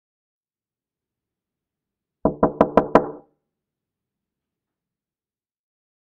دانلود آهنگ در زدن 4 از افکت صوتی اشیاء
دانلود صدای در زدن 4 از ساعد نیوز با لینک مستقیم و کیفیت بالا
جلوه های صوتی